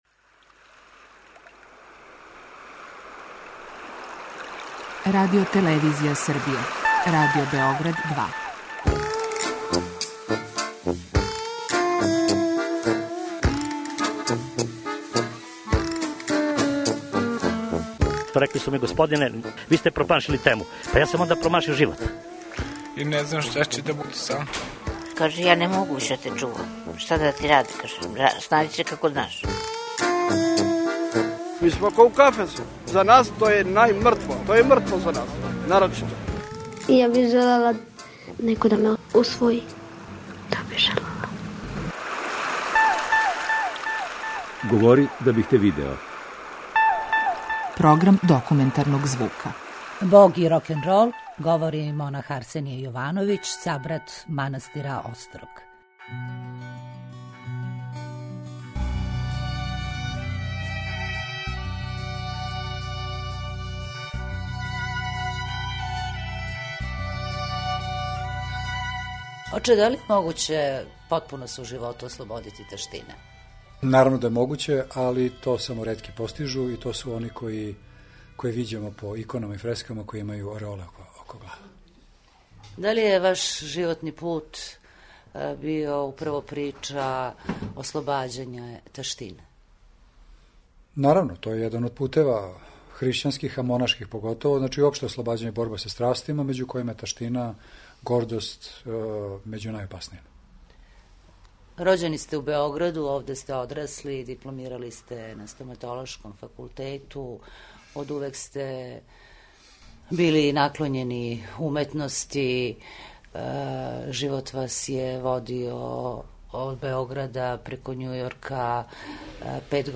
Документарни програм: Бог и рокенрол